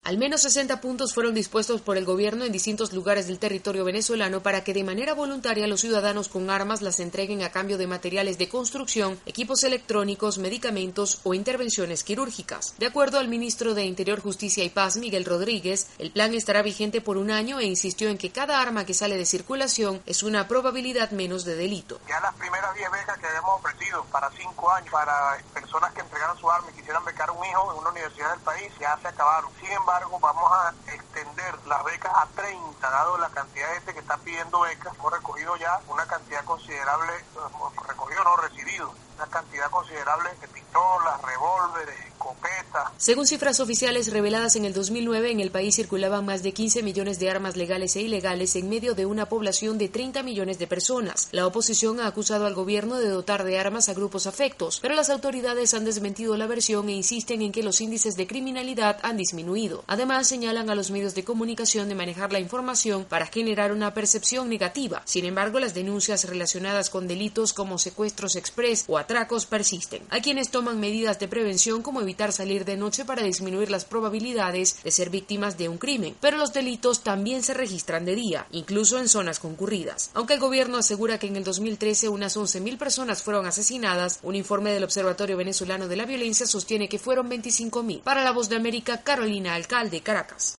Bajo la Mirada suspicaz de una parte de la población, las autoridades venezolanas iniciaron un plan de desarme que busca bajar los índices de violencia en el país. Desde Caracas informa